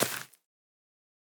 Minecraft Version Minecraft Version latest Latest Release | Latest Snapshot latest / assets / minecraft / sounds / block / azalea / break1.ogg Compare With Compare With Latest Release | Latest Snapshot
break1.ogg